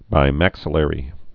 (bī-măksə-lĕrē)